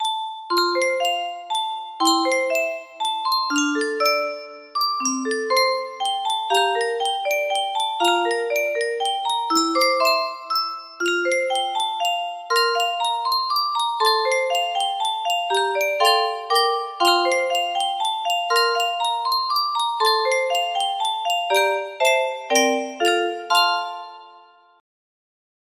브람스 자장가 music box melody
Grand Illusions 30 (F scale)